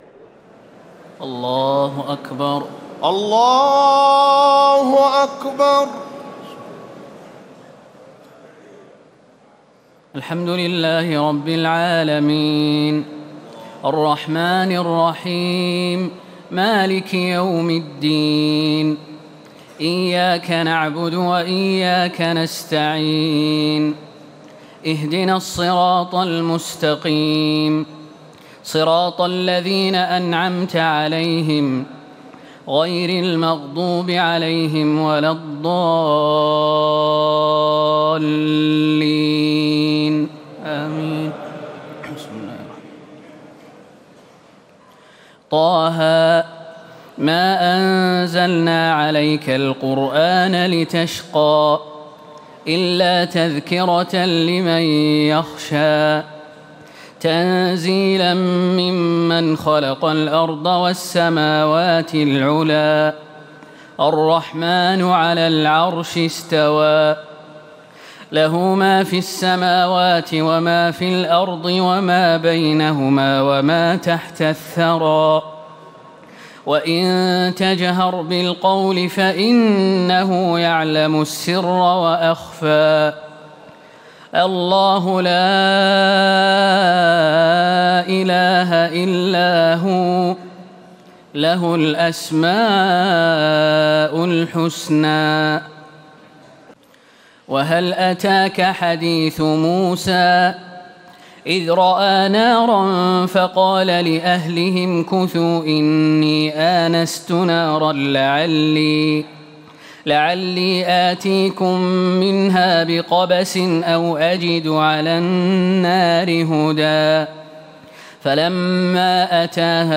تراويح ليلة 30 رمضان 1437هـ سورة طه كاملة Taraweeh 30 st night Ramadan 1437H from Surah Taa-Haa > تراويح الحرم النبوي عام 1437 🕌 > التراويح - تلاوات الحرمين